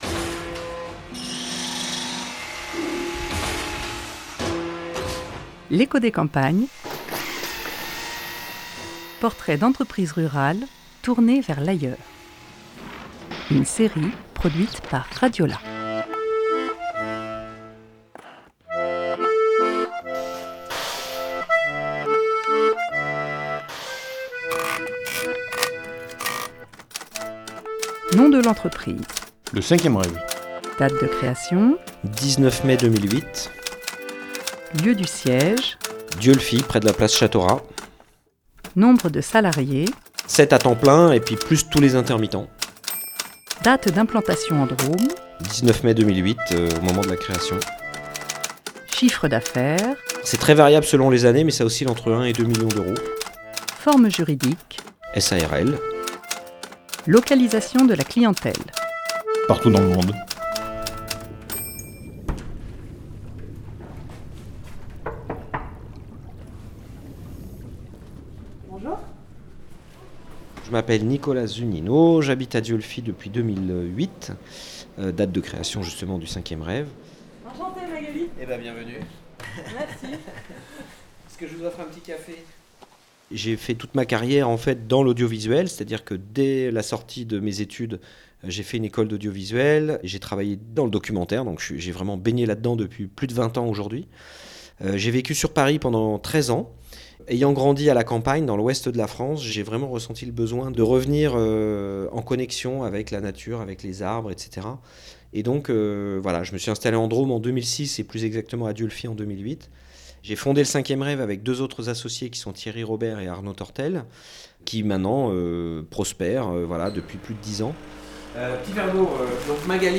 1 février 2021 16:27 | eco des campagnes, podcasts maison, reportage